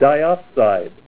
Help on Name Pronunciation: Name Pronunciation: Diopside
Say DIOPSIDE Help on Synonym: Synonym: Dillage   ICSD 100738   PDF 19-239